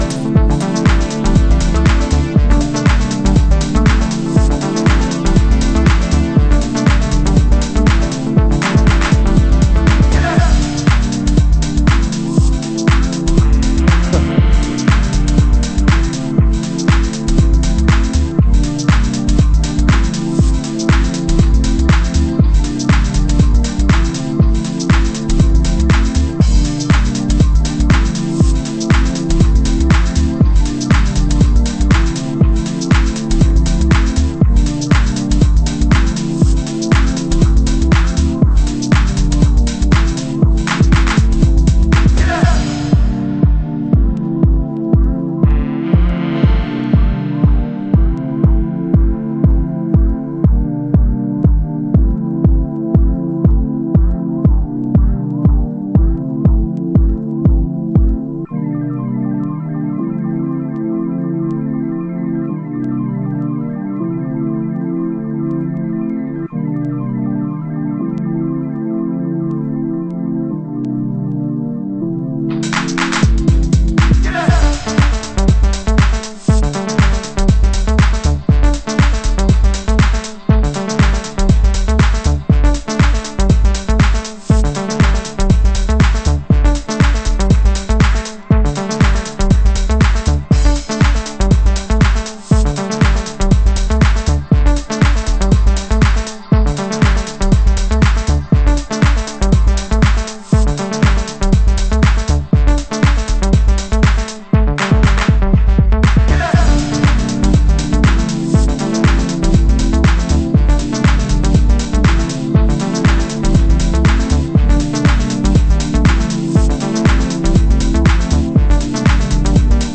盤質：B1に小傷 有/少しチリパチノイズ有